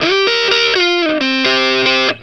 BLUESY3 C 90.wav